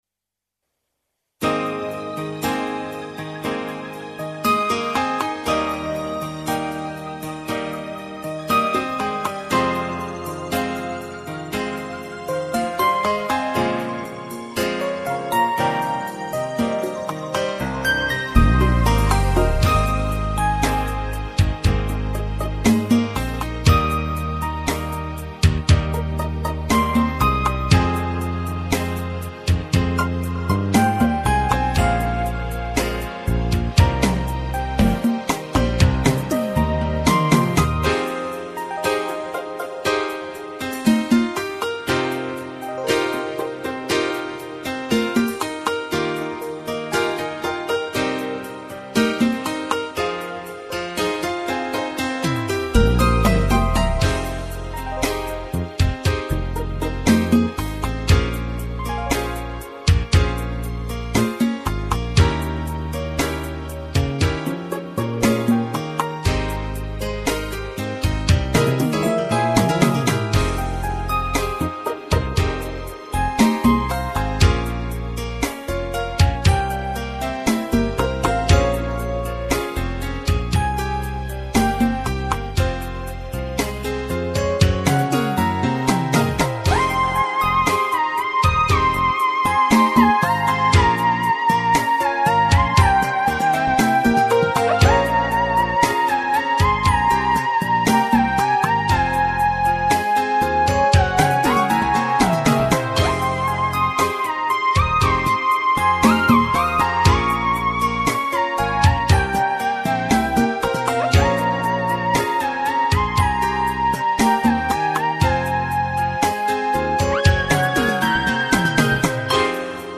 Вот звуки ts 10 ,когда я был студентом,мы писали на нем,когда у меня он был..